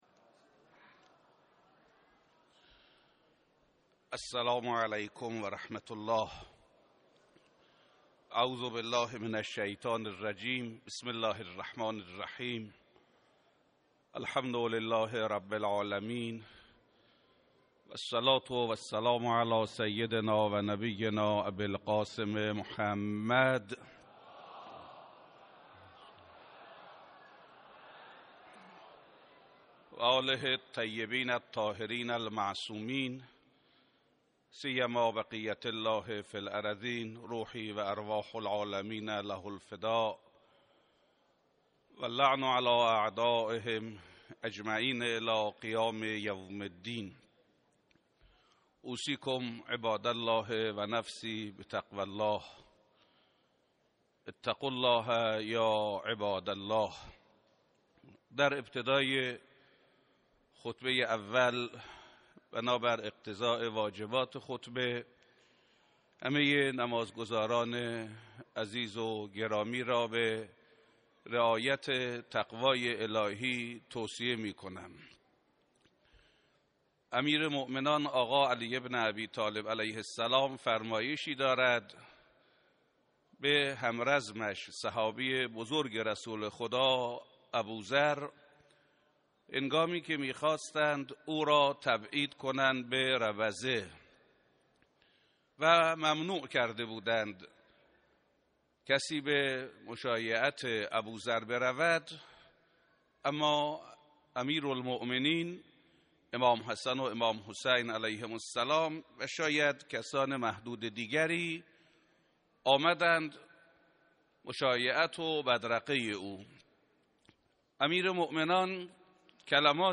نمازجمعه 14 آذر در مصلای امام خمینی(ره)اهواز به امامت حجت الاسلام حسن زاده امام جمعه موقت اهواز برگزار شد.
93.9.14-خطبه-ها.mp3